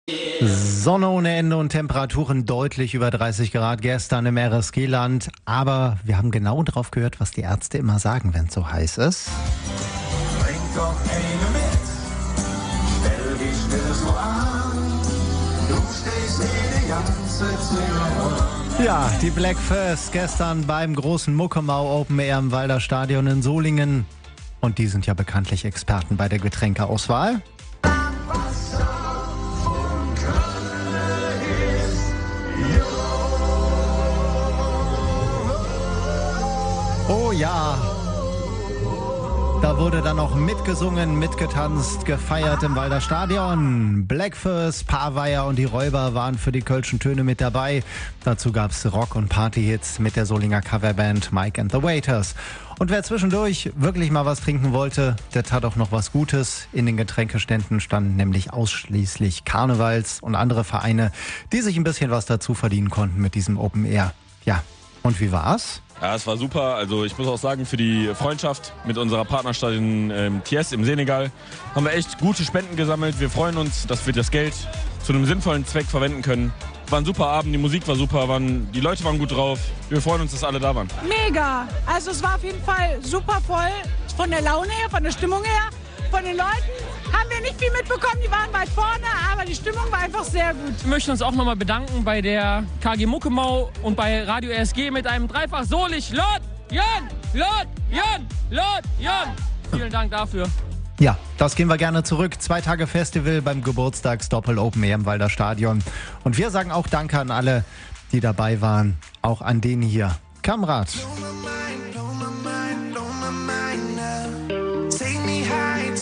Karneval im Sommer: Die Kölner Kultbands Räuber, Paveier und Bläck Fööss sorgten beim Muckemau Open Air im Walder Stadion in Solingen für ausgelassene Stimmung bei hochsommerlichen Temperaturen.